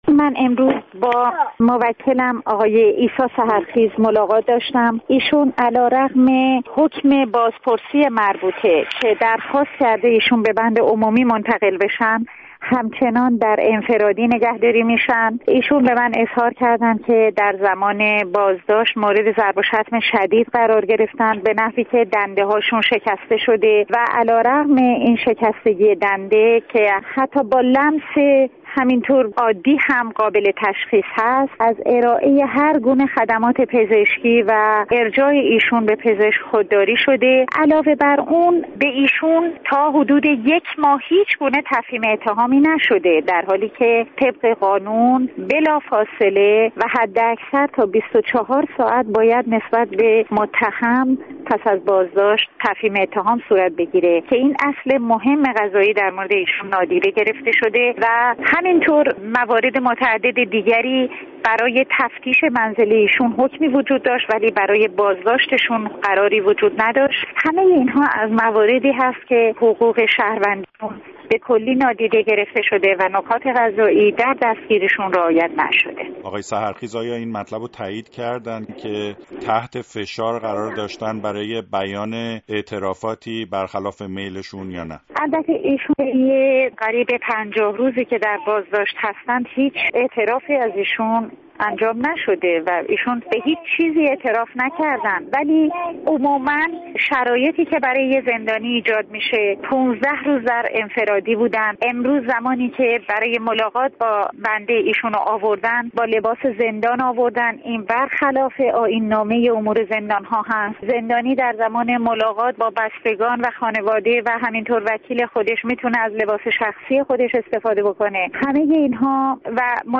گفت‌وگو با نسرین ستوده وکیل عیسی سحرخیز